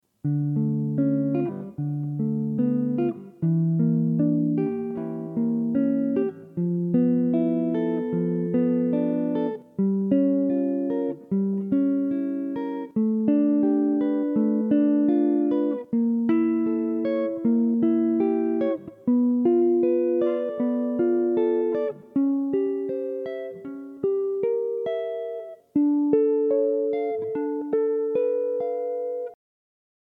Min-6th-and-Dim-Chords-borrowing-on-2nd.mp3